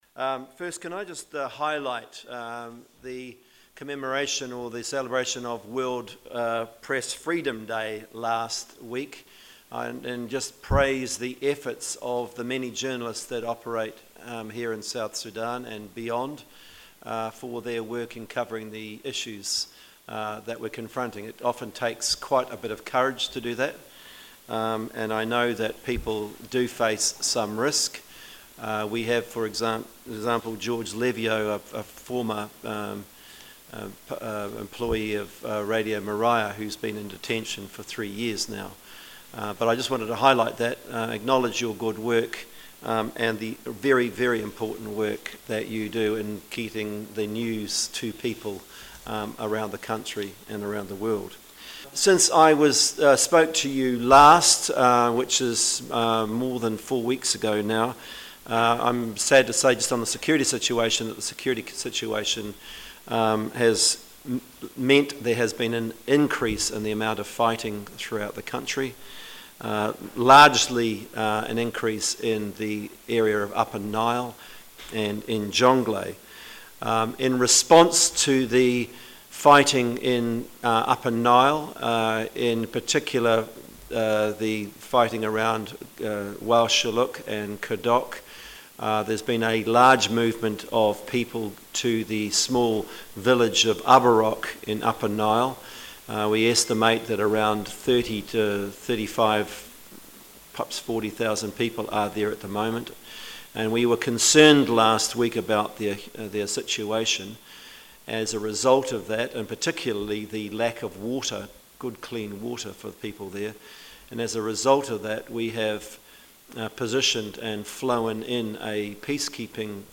(Slightly Edited audio) - The Special Representative of the Secretary General, who is also head of the UN mission in South Sudan, David Shearer, addressed a news conference in Juba, South Sudan on Thursday.